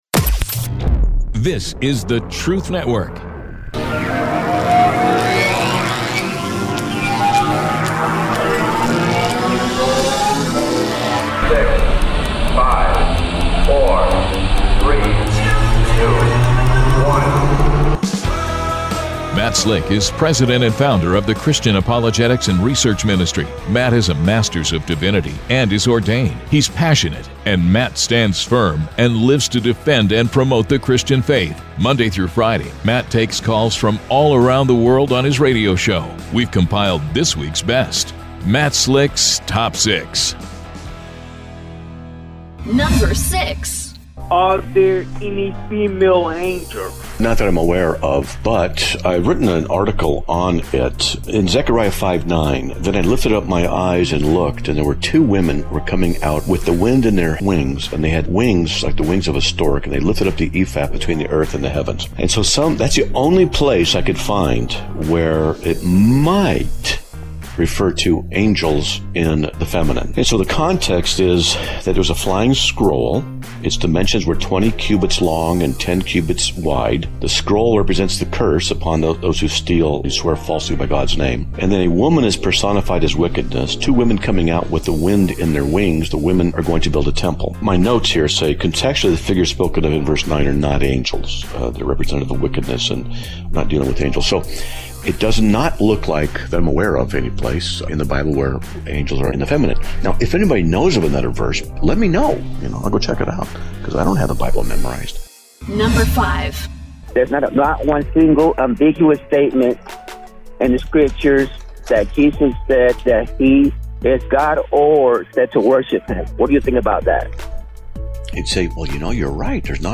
The sound bites are from Roy Rogers